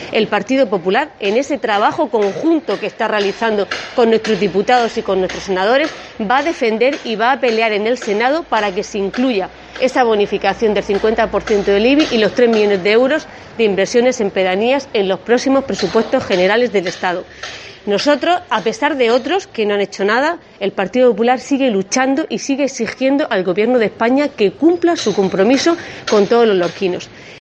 Rosa Medina, edil del PP sobre IBI